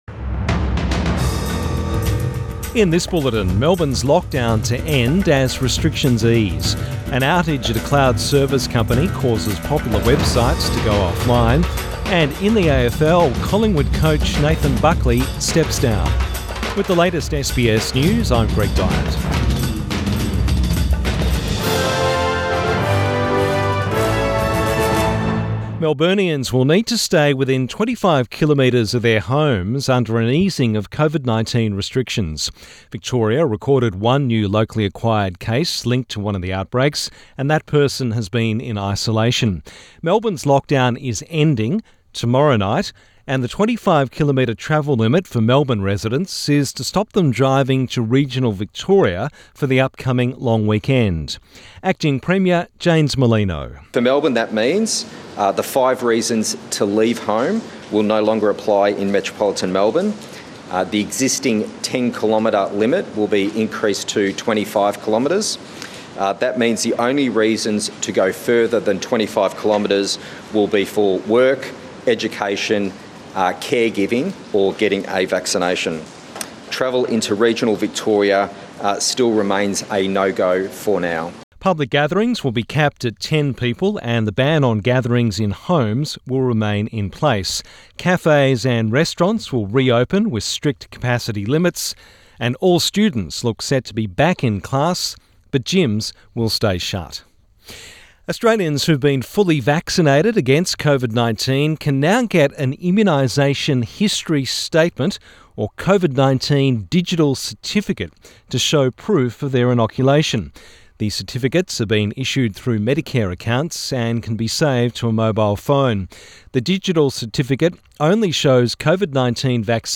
Midday bulletin 9 June 2021